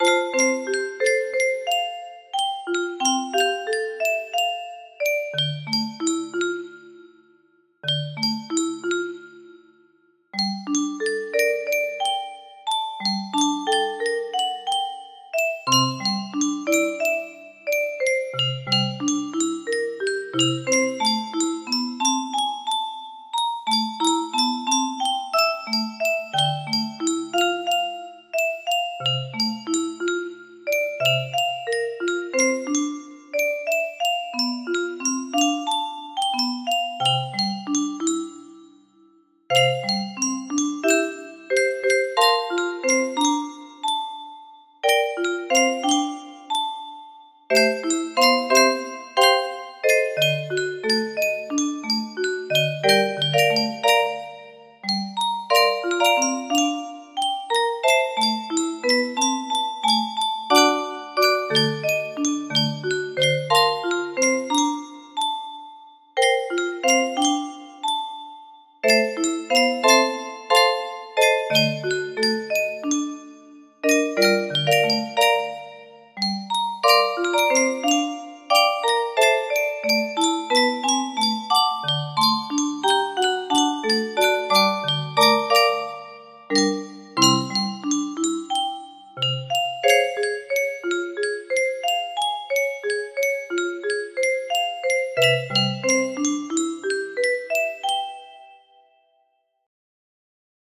Sd2 music box melody